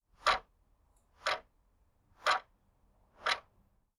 loopable-ticking-clock.wav